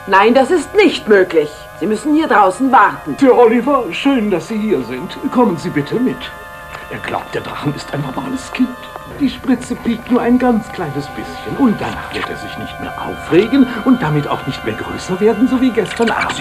Ich hätte da mal eine Münchner Trick-Synchro von 1989 mit den üblichen Verdächtigen.
Krankenschwester und Arzt